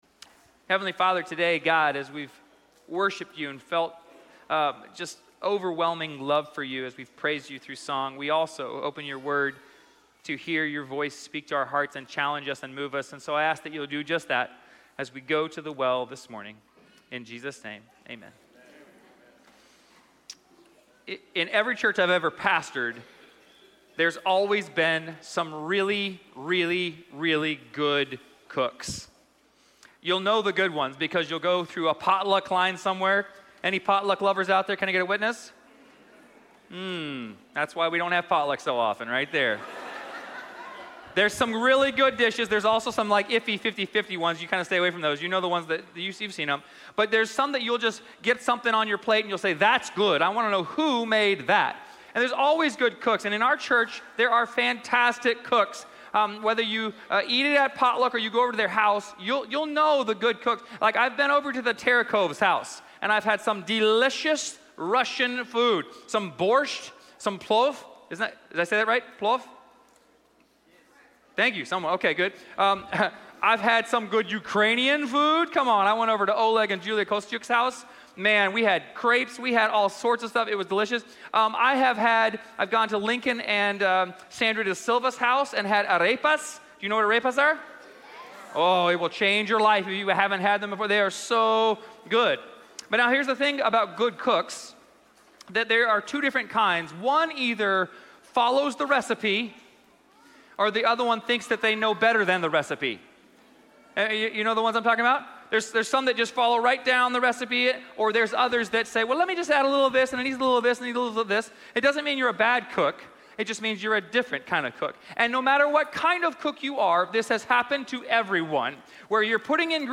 Forest Lake Church Sermon podcast